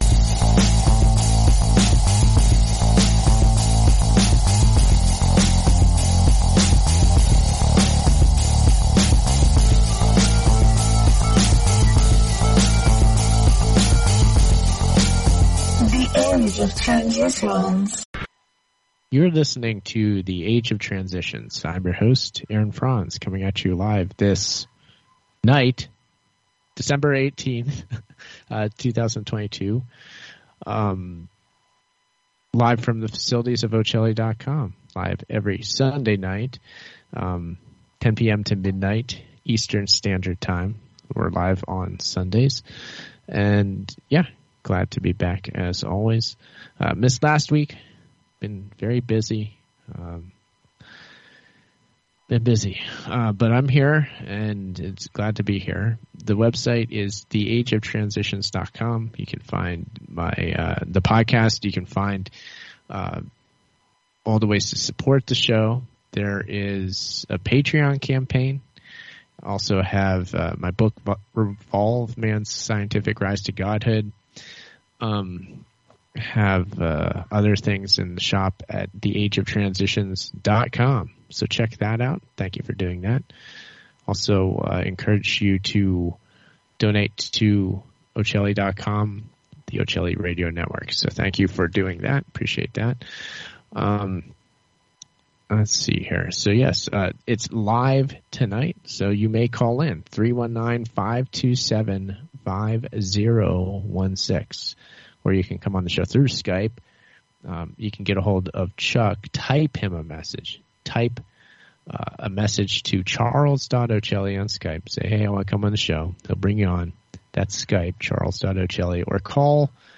testing live from Landers broadcast set up, ethernet, taking calls